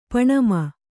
♪ paṇama